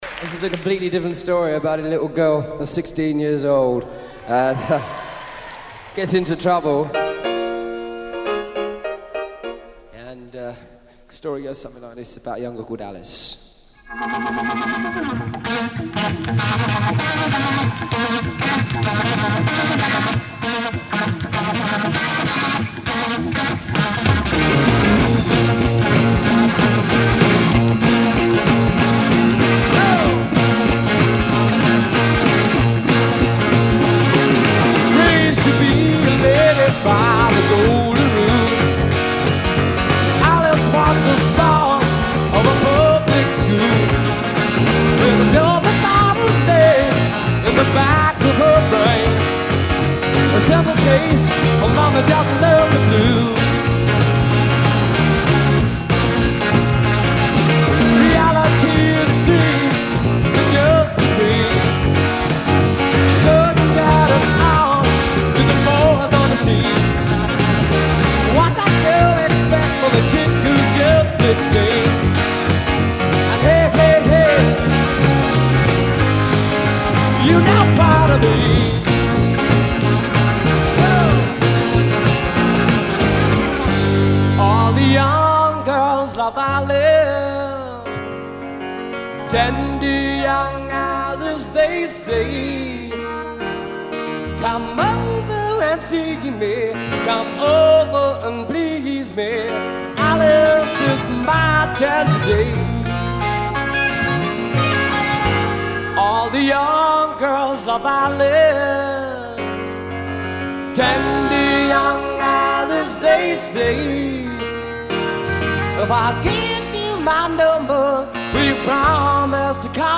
musical director, guitarist
at London’s venerable Hammersmith Odeon theater